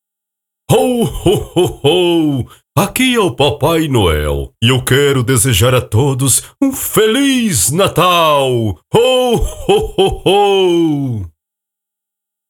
Voz PAPAI NOEL
Caricata